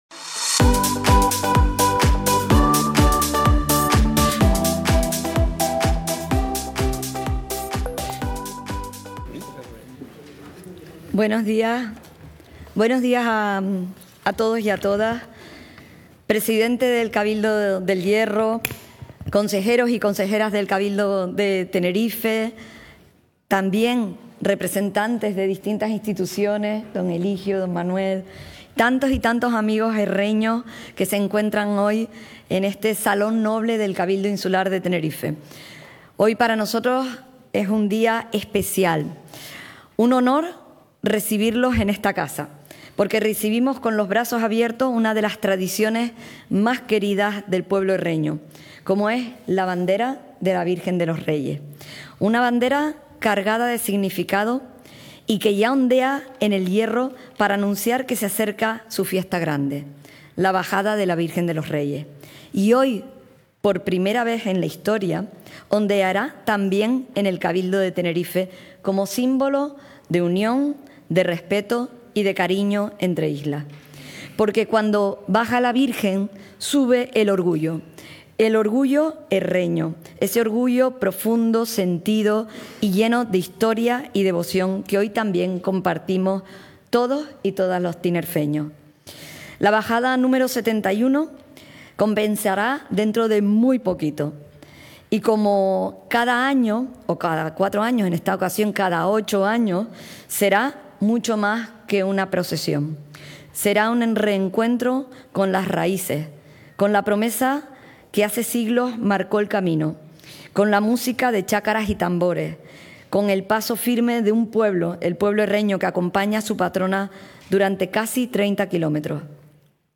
Recientemente emitido: El Cabildo de Tenerife acoge este lunes, 23 de junio, un acto simbólico de izado de bandera de la Virgen de los Reyes, que se realiza por primera vez en la historia de esta institución insular, con motivo de la LXXI Bajada...